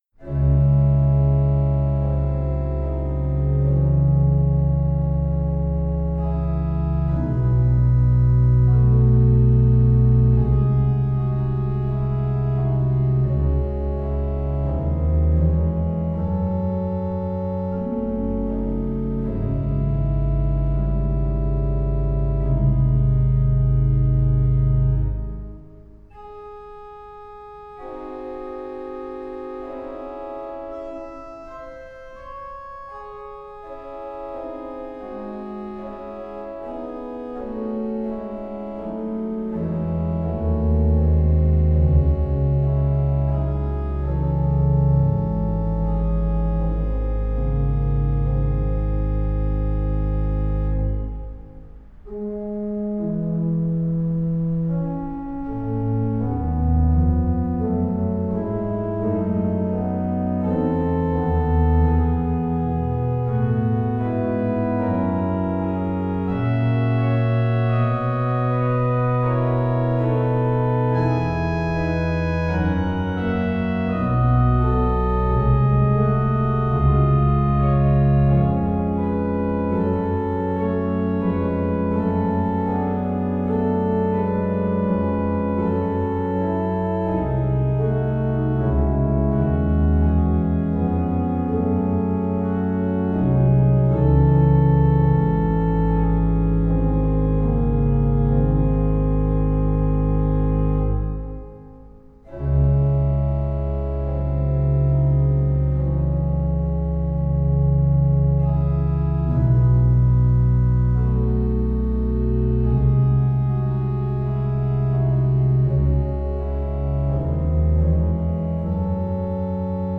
Organ in Hasse-Aula
Number of stops: 10
Setting: 1 Manual und Pedal
Orgel